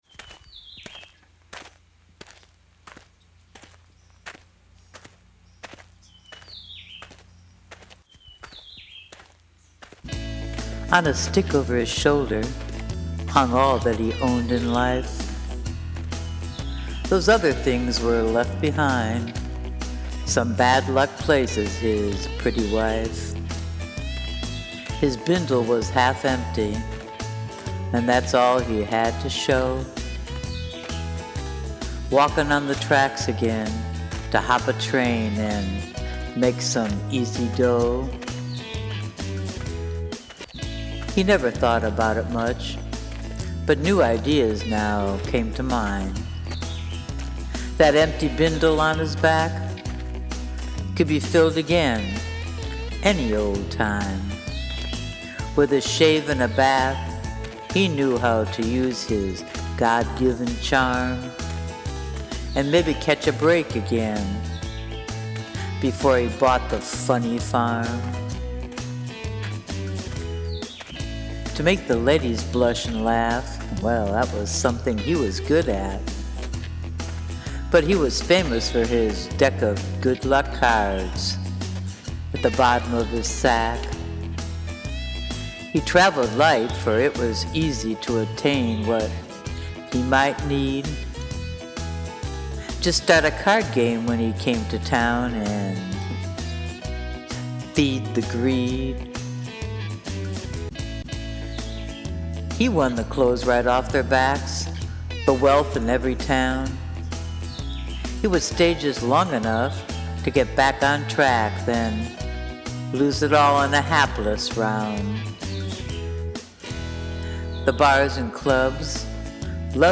your garage band music background was very convincing! i was impressed🙏🏼❤🙏🏼 you are good!!🙏🏼💕👍🏼🌹🤗
And the music / audio track is perfect; the subtle walking sound throughout, and your voice!
very light hearted. sweet! has that American vibe